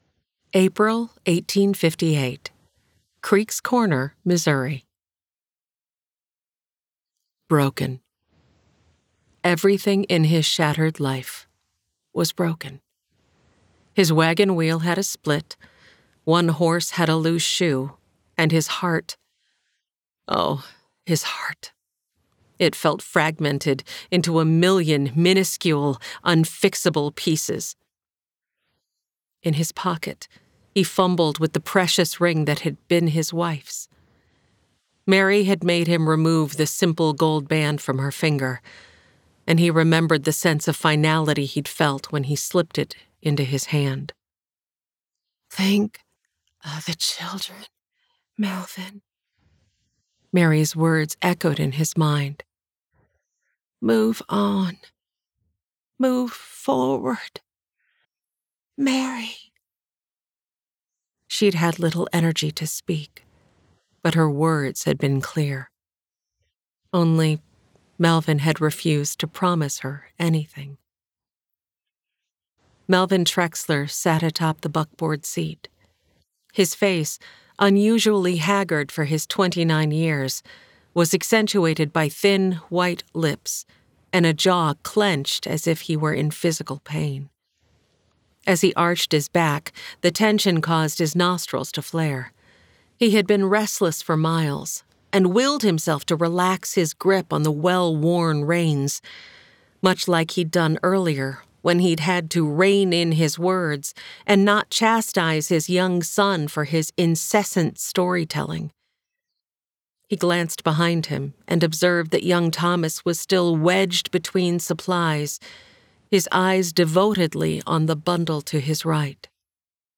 Sample Audiobook | Buy Audiobook